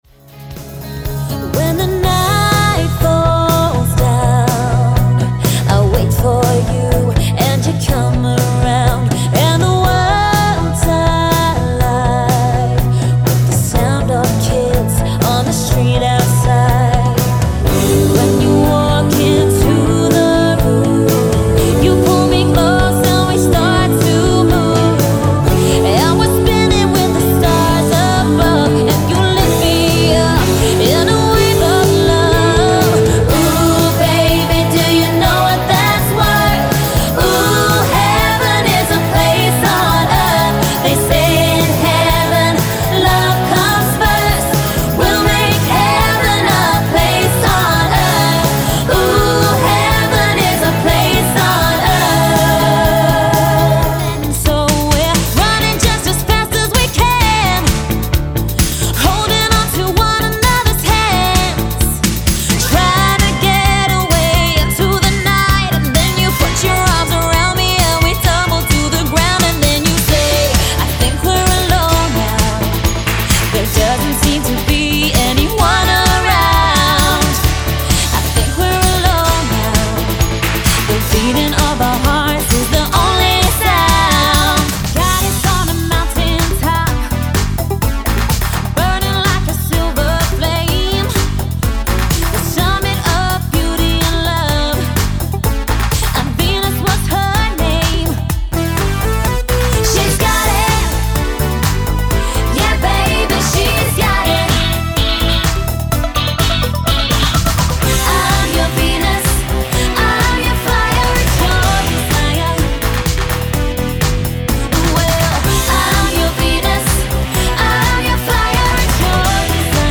• Fully Costumed 80s Tribute Show